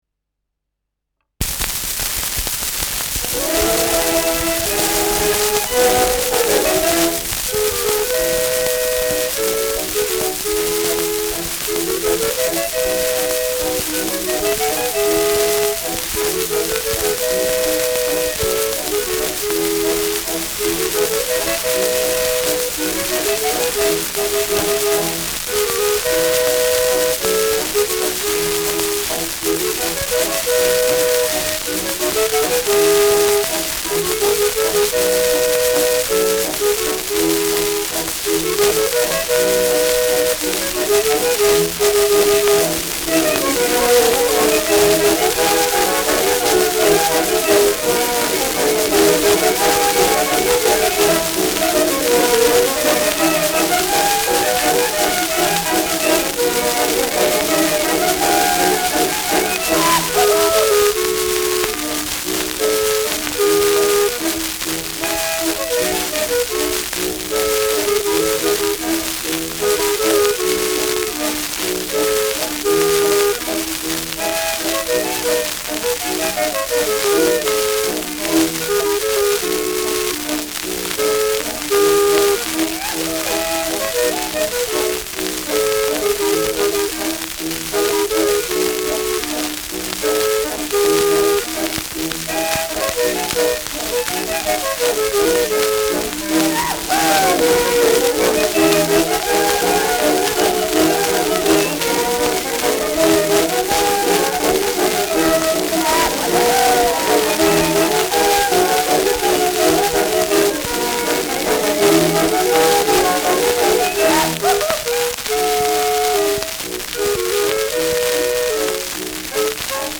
Schellackplatte
Tonrille: Kratzer Durchgehend Stärker : Berieb Durchgehend Leicht
ausgeprägtes Rauschen
Militärmusik des k.b. 1. Infanterie-Regiments, München (Interpretation)